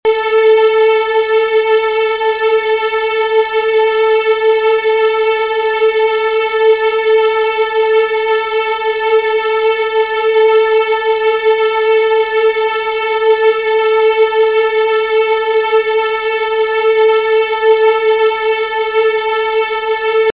Referenzbeispiele zum Stimmen der E-, A-, D-, G- Geigensaite
Wenn Sie auf die folgenden Links klicken, hören Sie, wie die Saiten klingen und können ihre Geige danach stimmen:
A-Saite (440 Hz - mp3):
geige-stimmen-a-saite.mp3